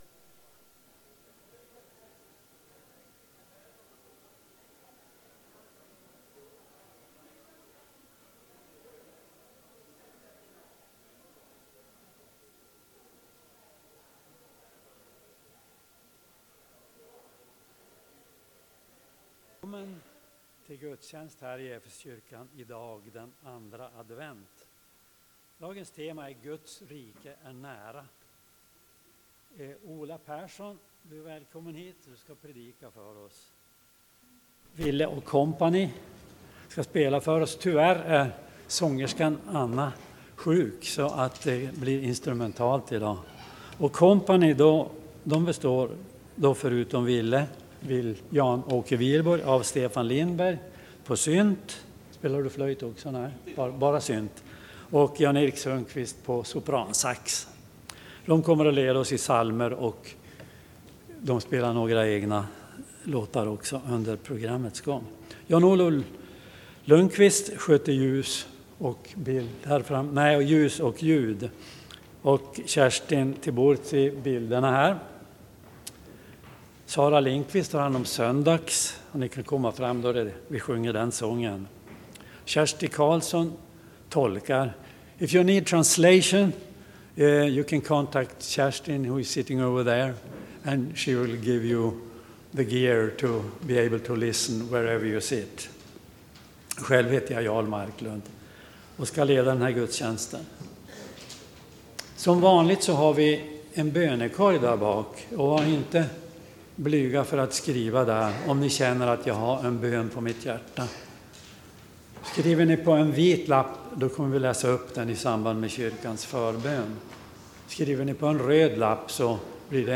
Mässa på andra Advent
Lyssna gärna till gudstjänsten!